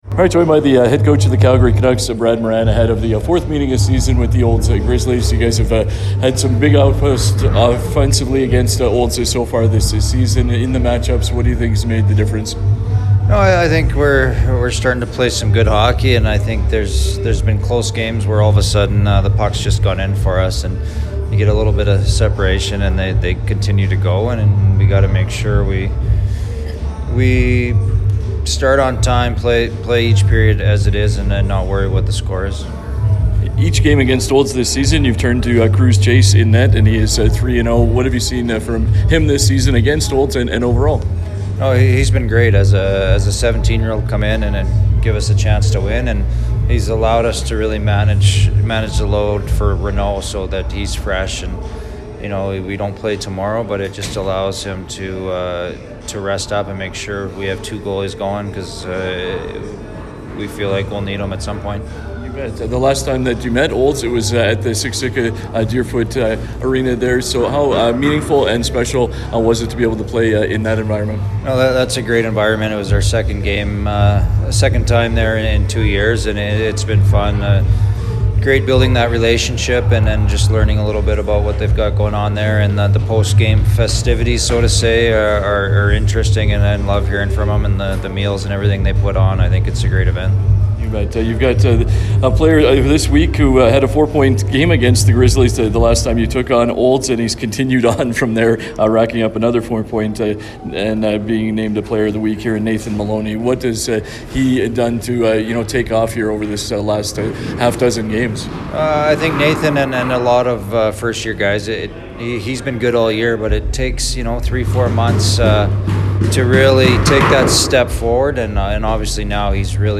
A tale of two teams heading in opposite directions was heard on 96.5 The Ranch on February 15th.